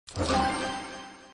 解锁新列音效.MP3